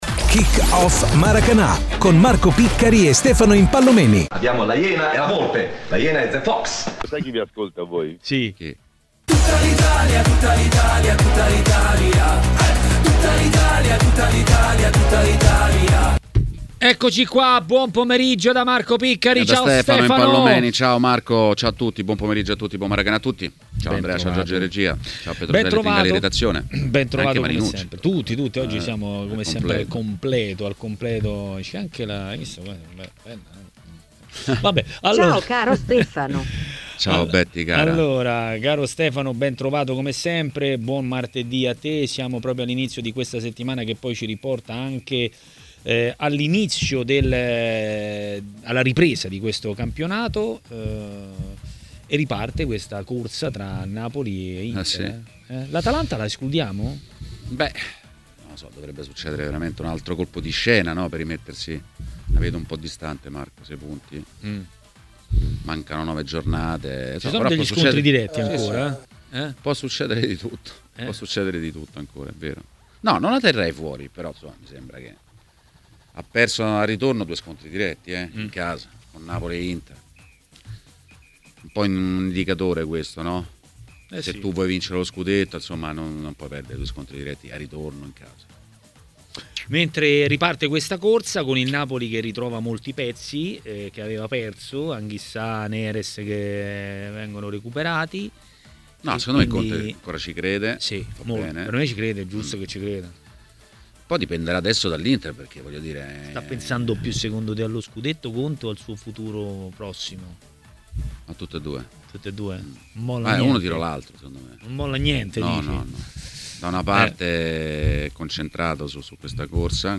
A parlare dei temi del giorno a TMW Radio, durante Maracanà, è stato il giornalista ed ex calciatore